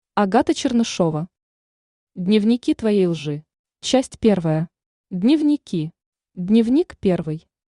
Аудиокнига Дневники твоей лжи | Библиотека аудиокниг